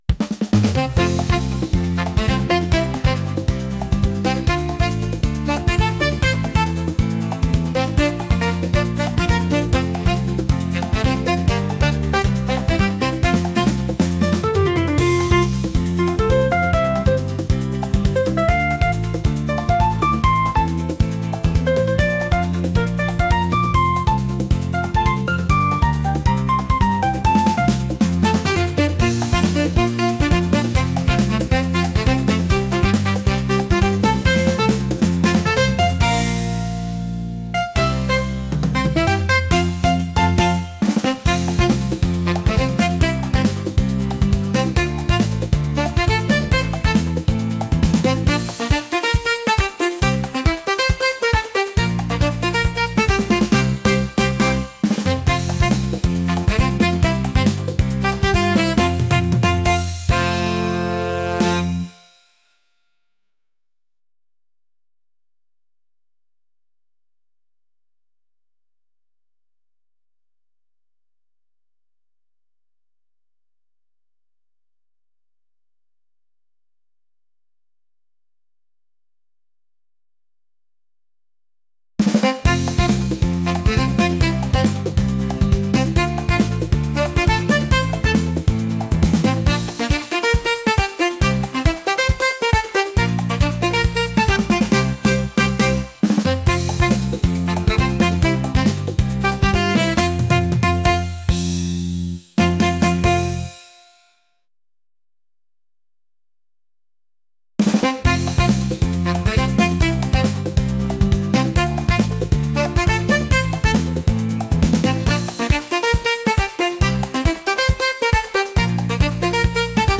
jazz | latin | upbeat